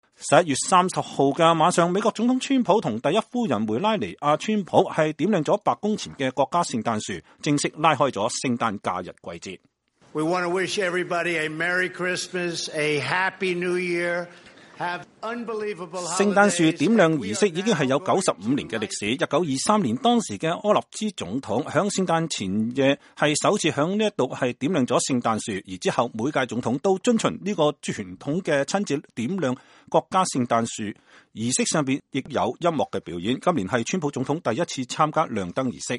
11月30號晚上美國總統川普和第一夫人梅拉尼婭·川普點亮白宮前的國家聖誕樹，正式拉開聖誕假日季節。